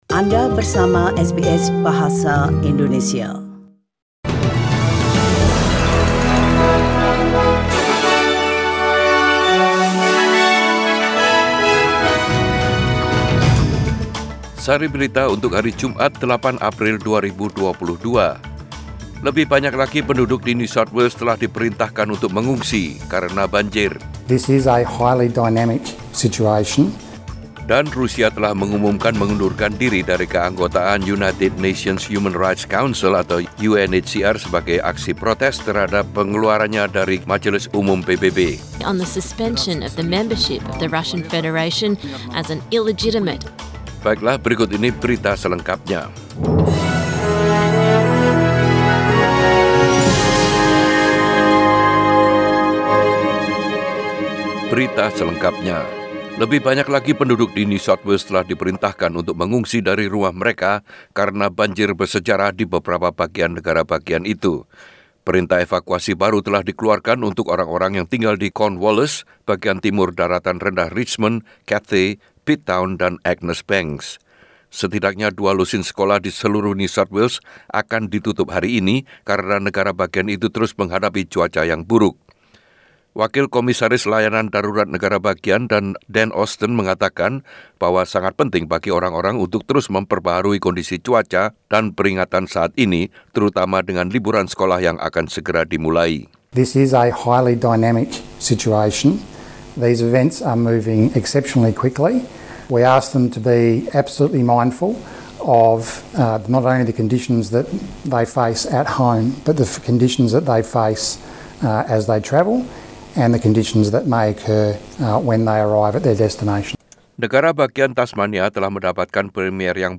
Warta Berita Radio SBS Program Bahasa Indonesia.